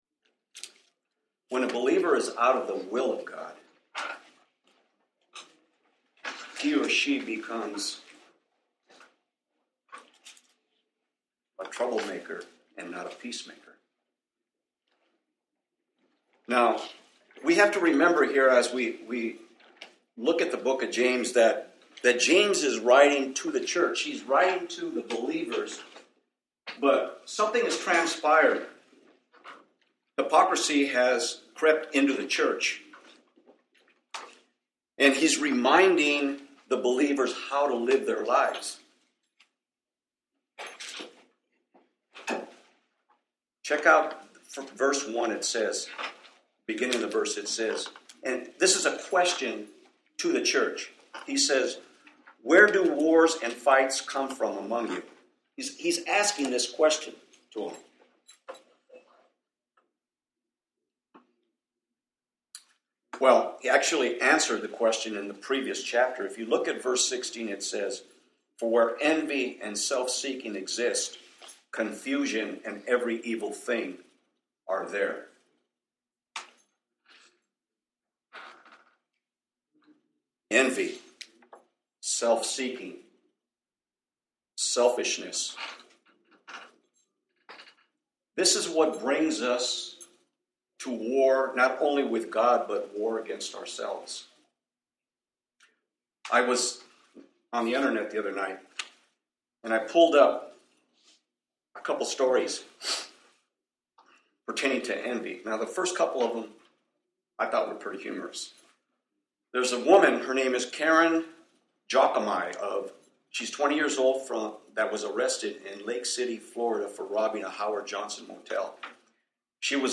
James 4:1-10 Service: Wednesday Night James 4 « Stand in ALL of God’s Armor N52